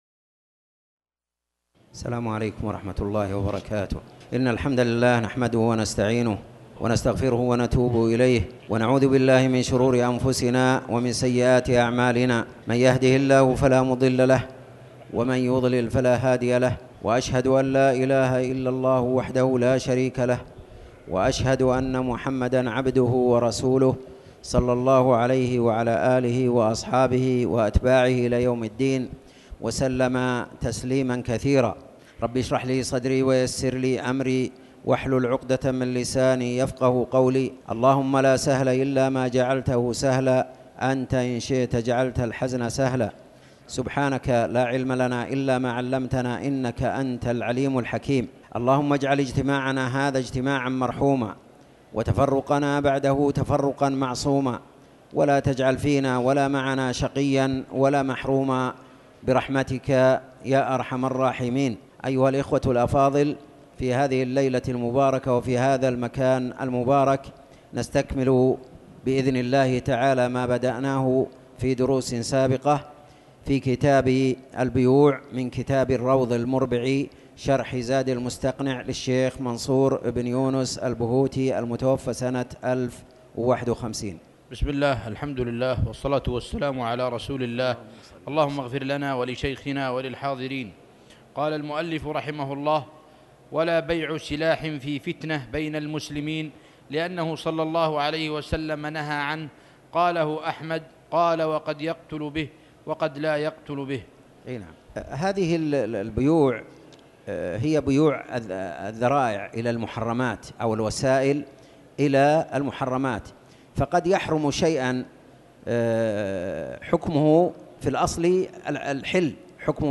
تاريخ النشر ٤ جمادى الآخرة ١٤٣٩ هـ المكان: المسجد الحرام الشيخ